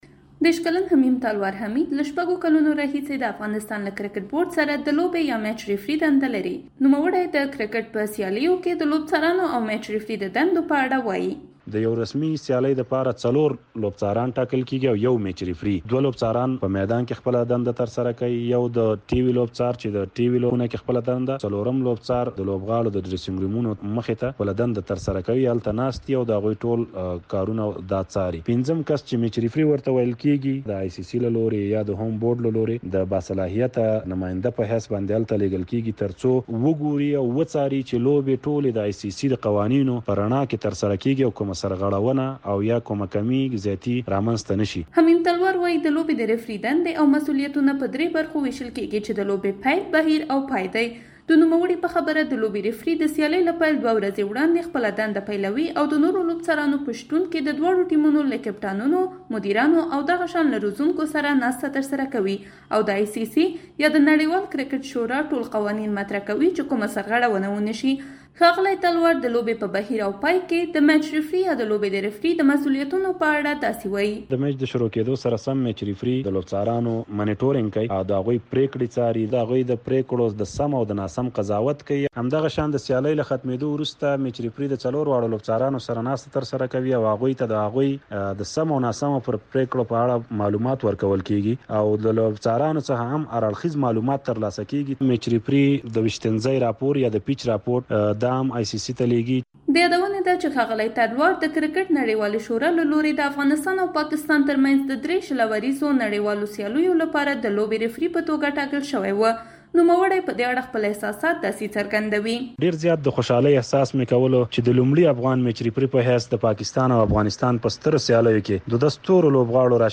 په کرکټ کې د ميچ ريفري په اړه پوهېږئ؟ له يو افغان ريفري سره خبرې
کرکټ راپور